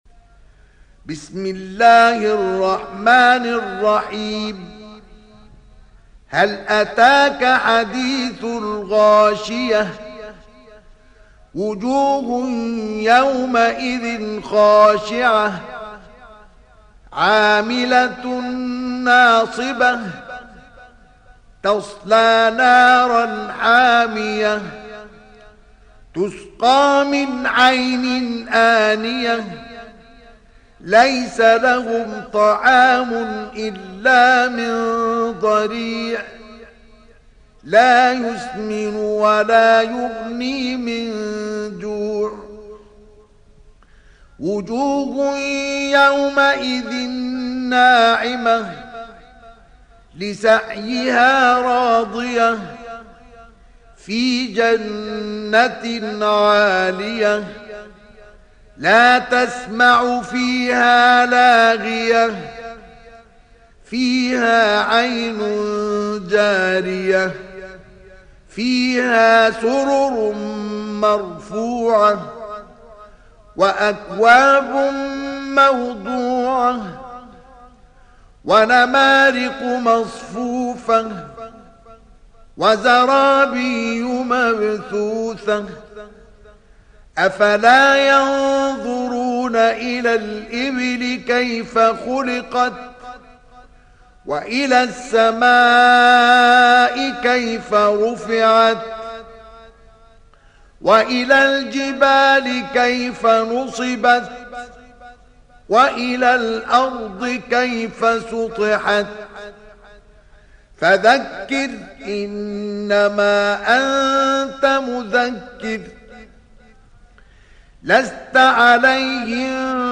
Surah Al Ghashiyah Download mp3 Mustafa Ismail Riwayat Hafs from Asim, Download Quran and listen mp3 full direct links